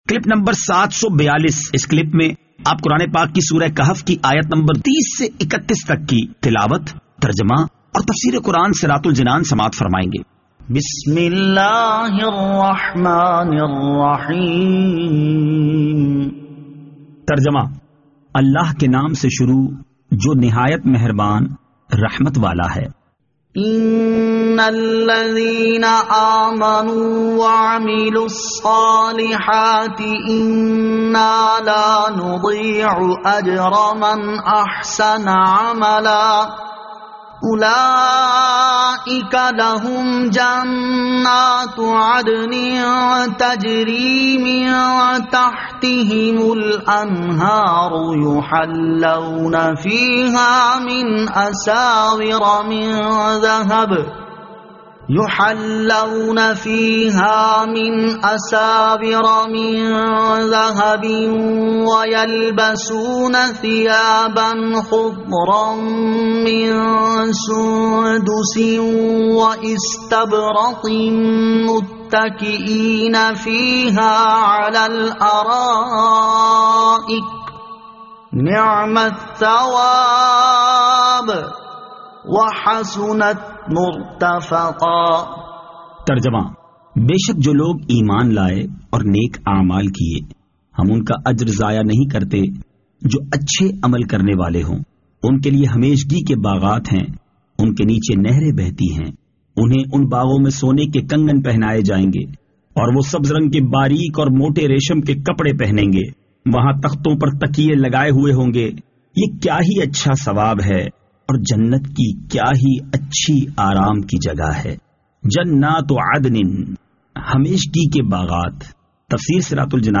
Surah Al-Kahf Ayat 30 To 31 Tilawat , Tarjama , Tafseer